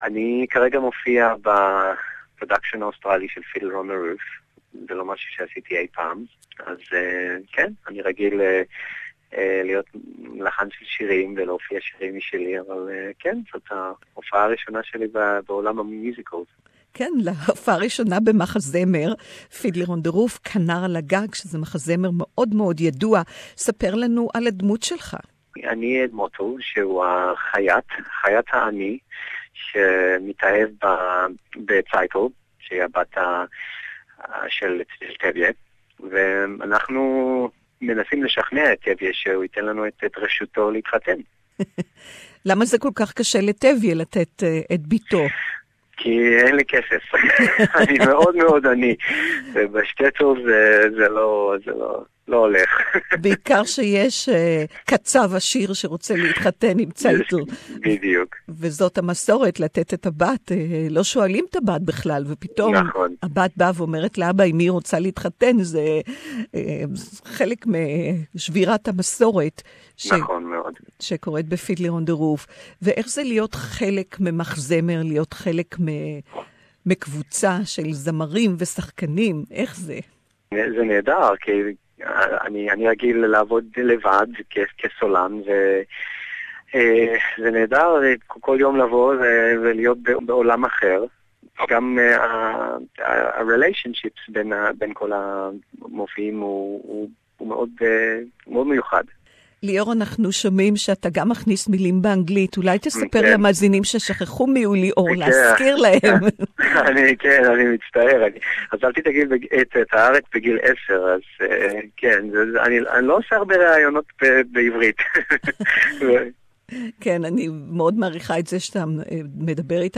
Lior " Fiddler on the Roof" Hebrew interview